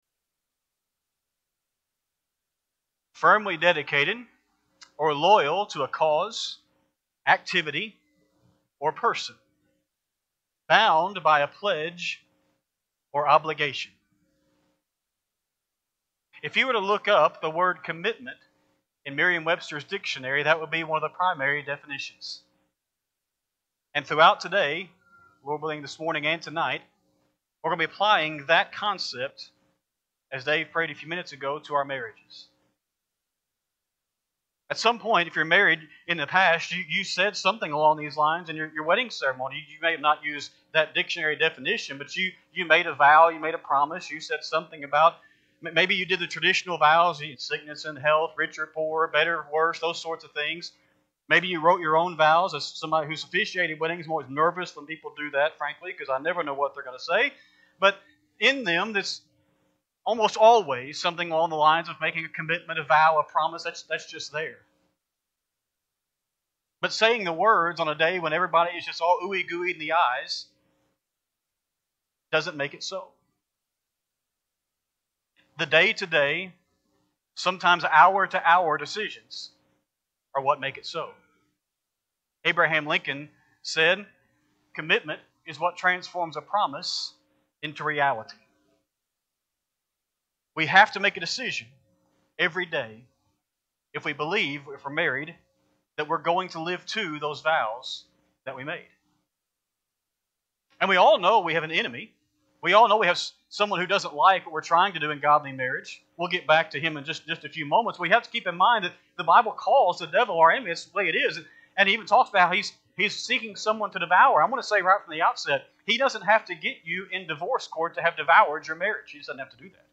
3-15-26-Sunday-AM-Sermon.mp3